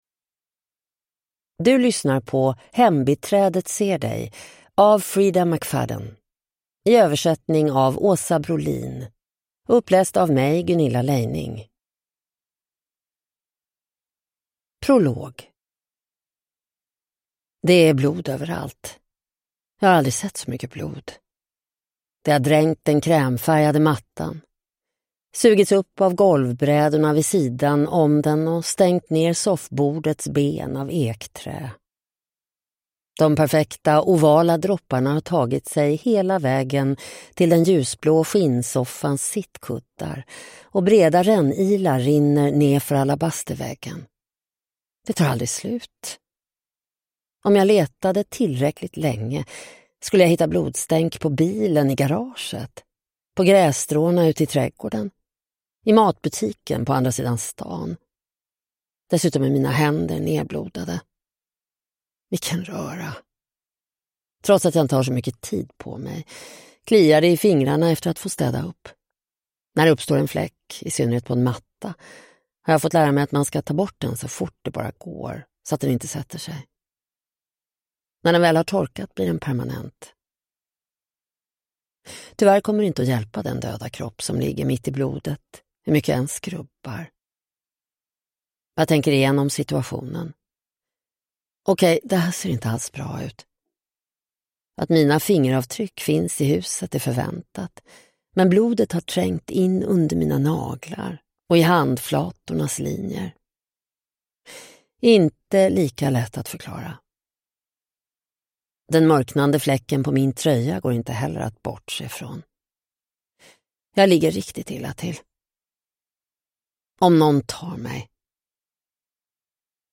Hembiträdet ser dig (ljudbok) av Freida McFadden